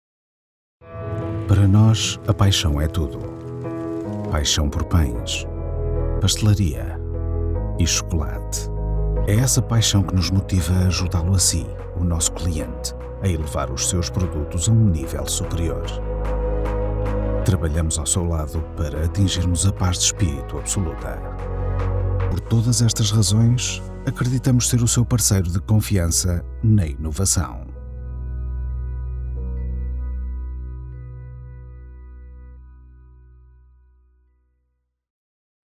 Portuguese Professional Voice Actor.
Sprechprobe: eLearning (Muttersprache):
Baritone male voice with pleasant, warm, calm, smooth and vibrant tone.